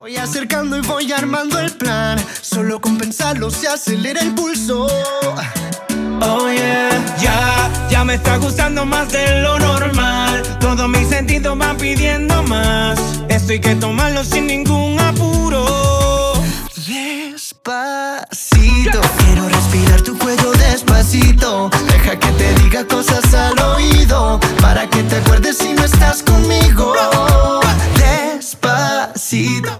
• Pop Latino